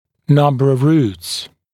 [‘nʌmbə əv ruːts][‘намбэ ов ру:тс]количество корней